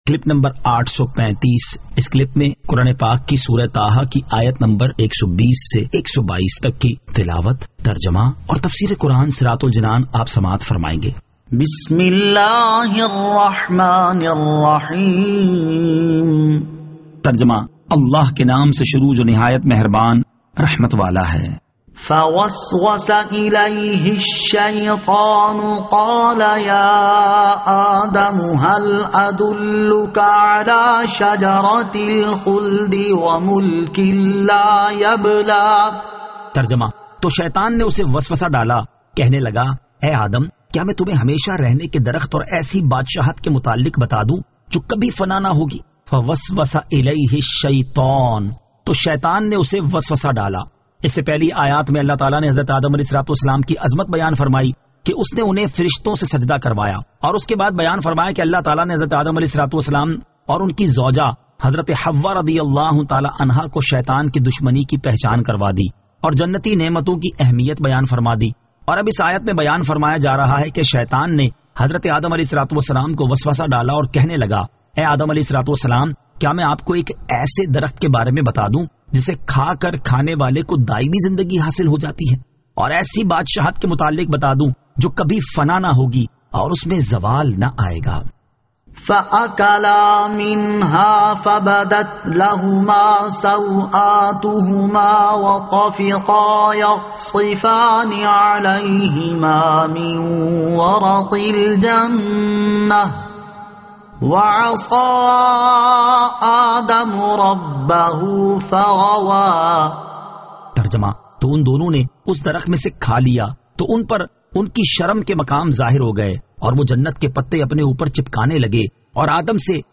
Surah Taha Ayat 120 To 122 Tilawat , Tarjama , Tafseer